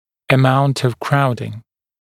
[ə’maunt əv ‘kraudɪŋ][э’маунт ов ‘краудин]степень скученности